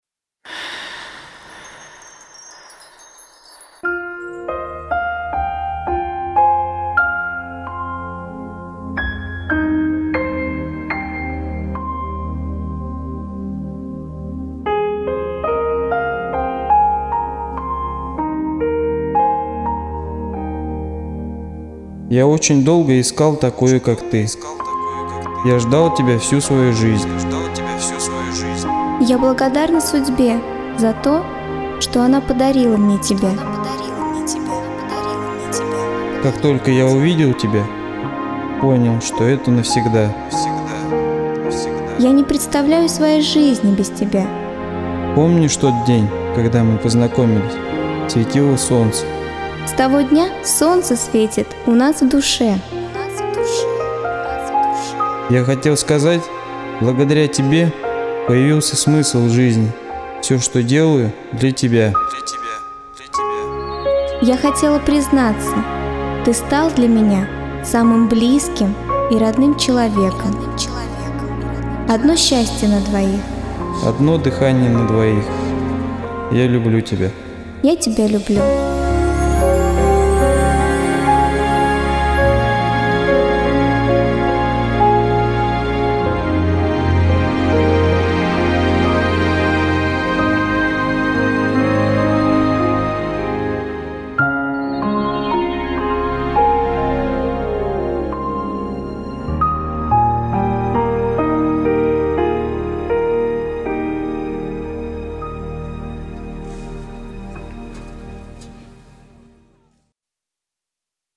Красивый звук с голосами парня и девушки: признание в любви на русском